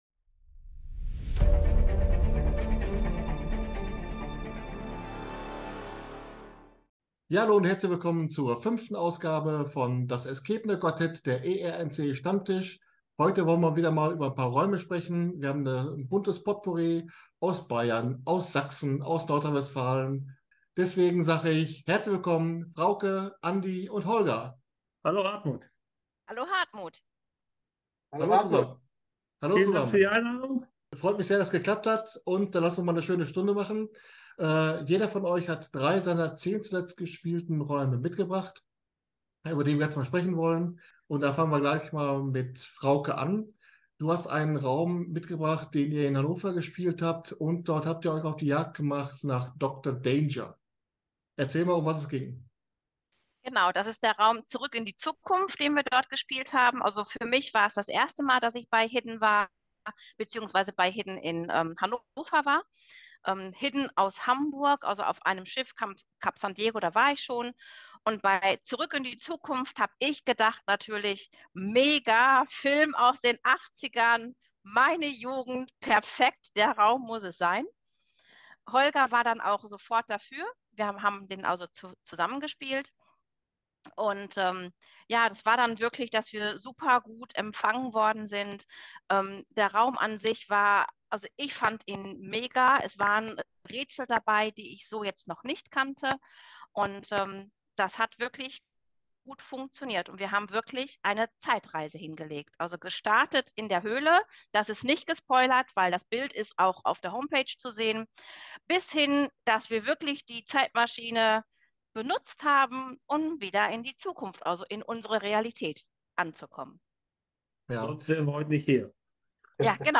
"Das escapende Quartett" ist der Stammtisch des ERNC. Hier unterhalte ich mich mit Spielerinnen und Spielern über Escape Rooms in Deutschland.